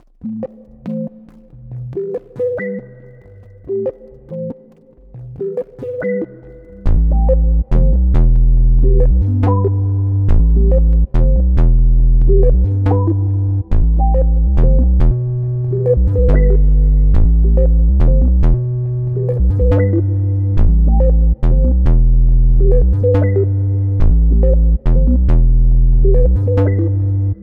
HALF [TREESOUND] NIGHTCRAWLERS 140BPM.wav